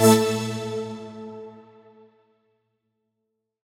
FR_ZString[hit]-A.wav